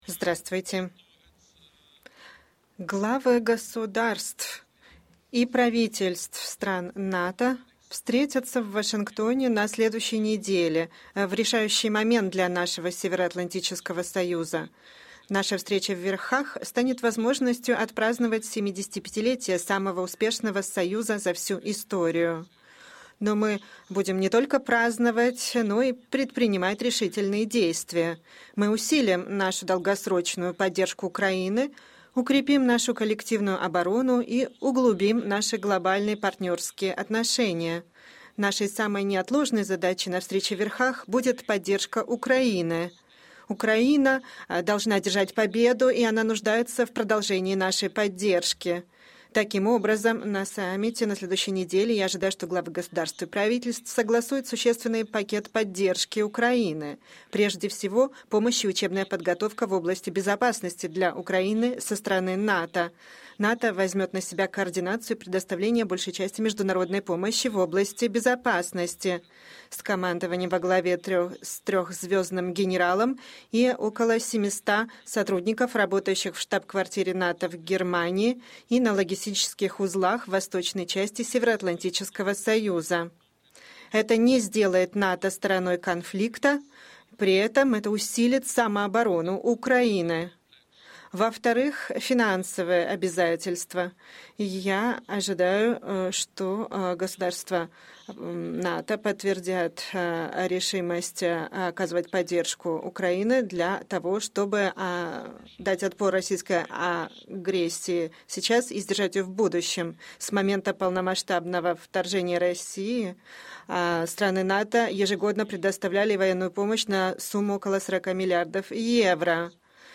Pre-summit press conference
by Secretary General Jens Stoltenberg ahead of the NATO Summit in Washington